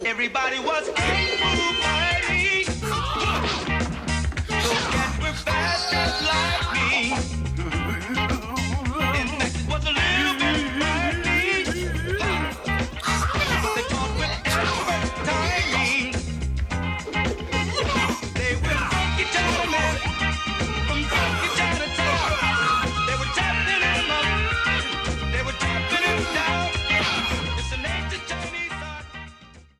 Short music sample of a cover song